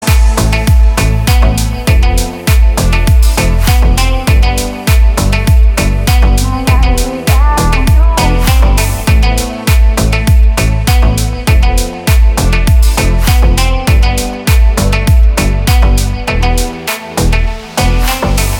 ритмичные
восточные мотивы
спокойные
красивая мелодия
Жанр: Deep house